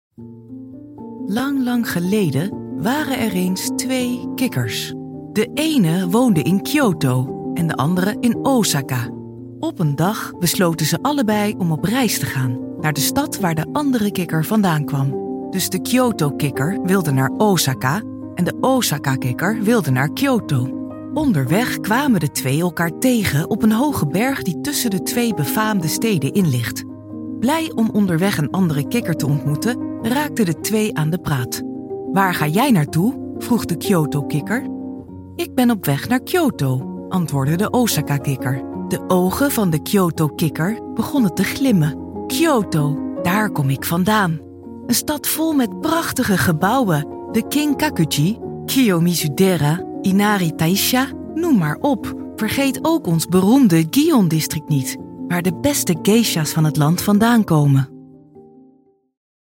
Natürlich, Verspielt, Zugänglich, Vielseitig, Freundlich
Audioguide